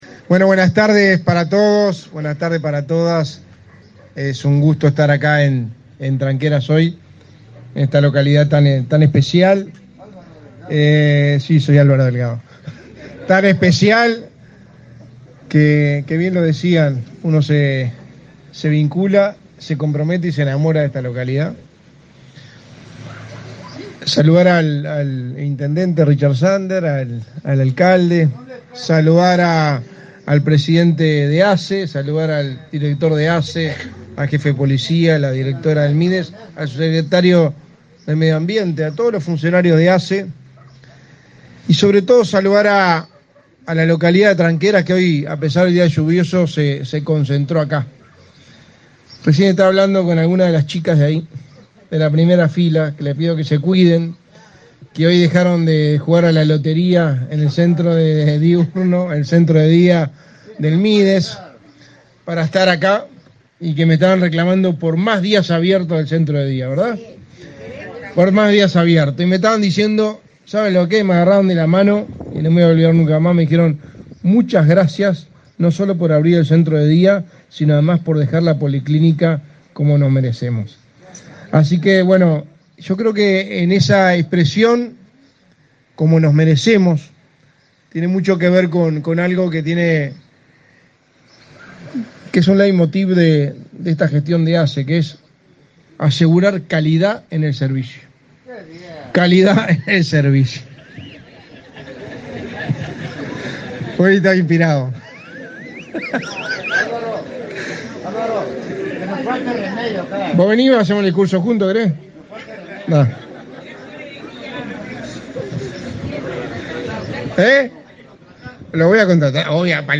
Palabras del secretario de la Presidencia, Álvaro Delgado
Palabras del secretario de la Presidencia, Álvaro Delgado 01/11/2023 Compartir Facebook X Copiar enlace WhatsApp LinkedIn El secretario de la Presidencia, Álvaro Delgado, junto a autoridades de ASSE, inauguró, este 1.° de noviembre, las obras de remodelación de la policlínica de Tranqueras, en el departamento de Rivera.